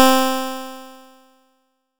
nes_harp_C4.wav